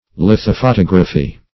Search Result for " lithophotography" : The Collaborative International Dictionary of English v.0.48: Lithophotography \Lith`o*pho*tog"ra*phy\, n. [Litho- + photography.]